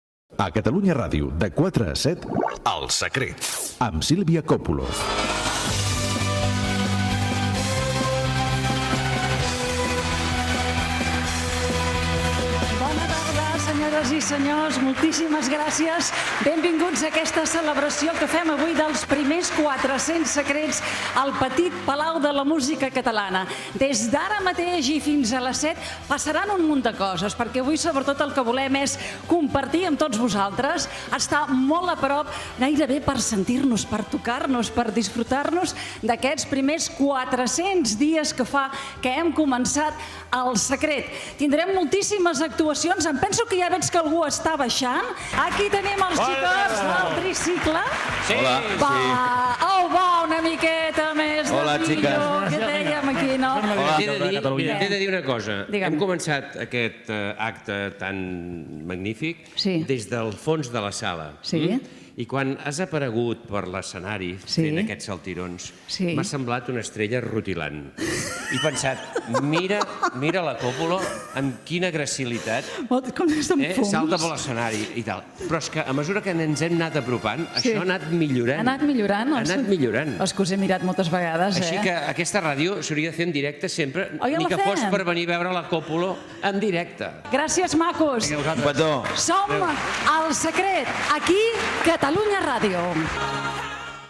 Careta del programa, presentació inicial de l'edició 400 des del Petit Palau de la Música Catalana. Intervenció del Tricicle, amb paraules de Carles Sans.
Entreteniment